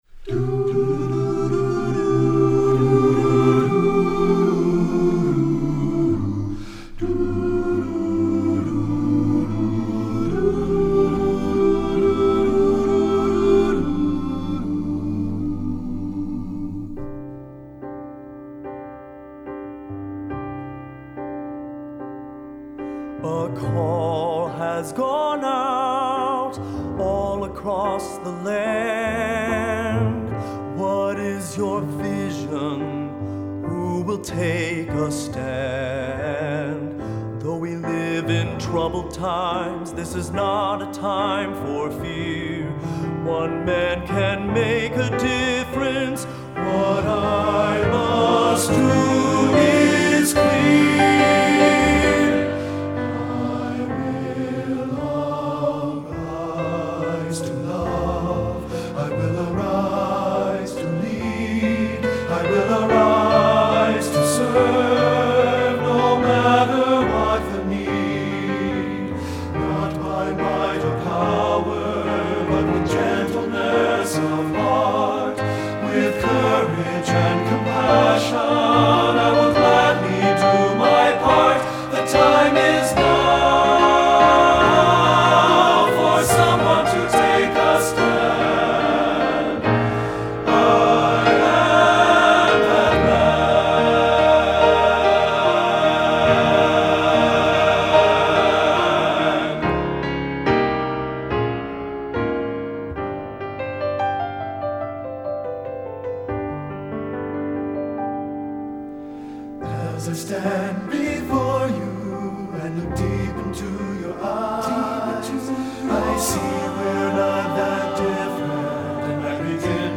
secular choral
TTBB, sample